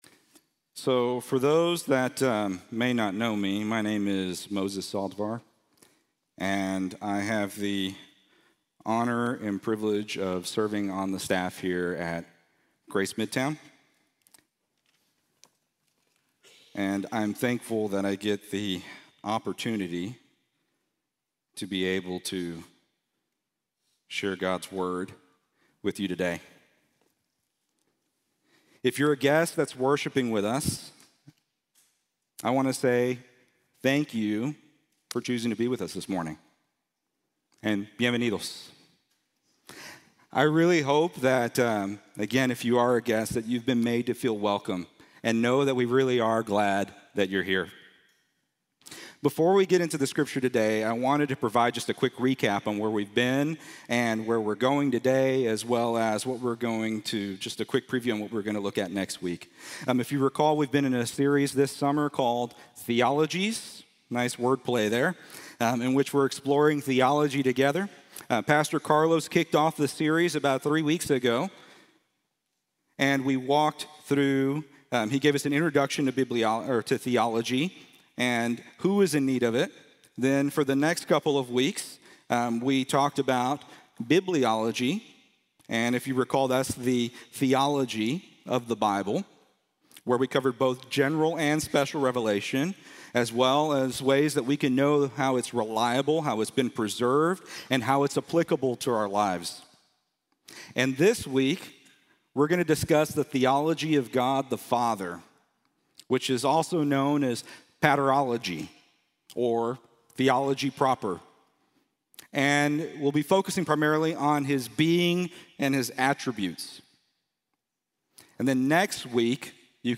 An Overwhelmingly Accessible and Knowable God | Sermon | Grace Bible Church